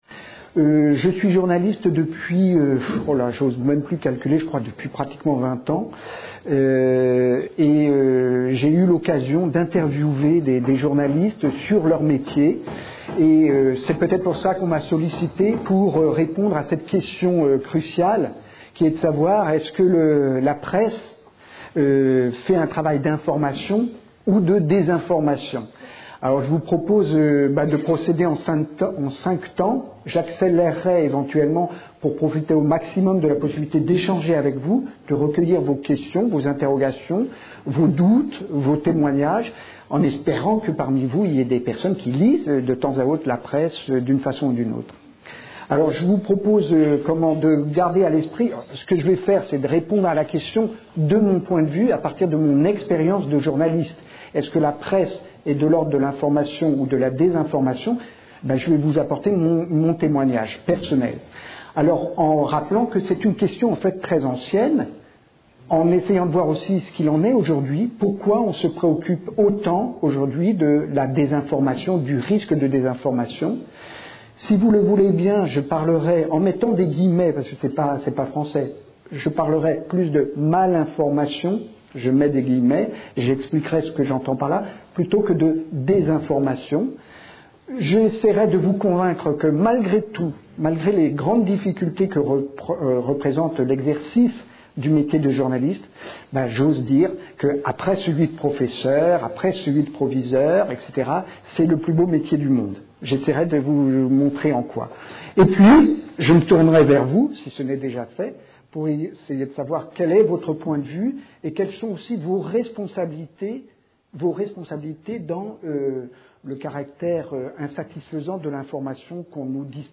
Une conférence de l'UTLS au Lycée La presse